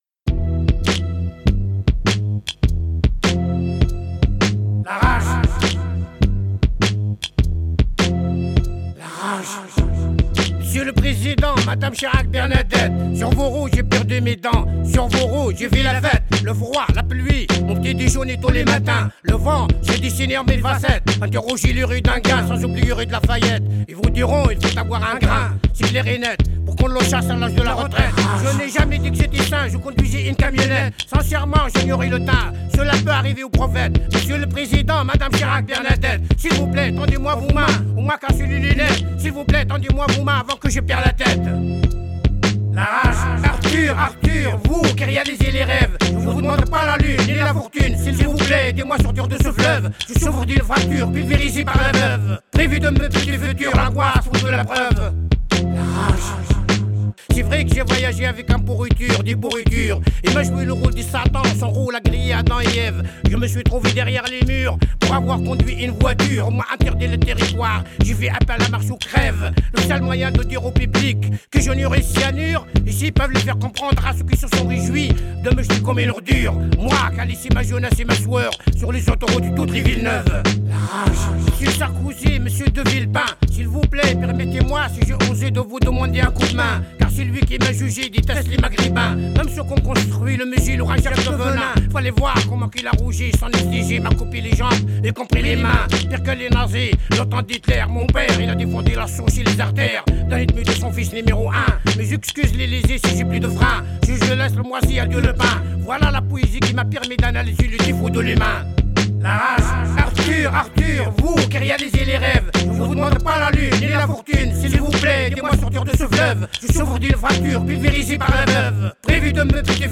présente et chante Gaston Couté
Émission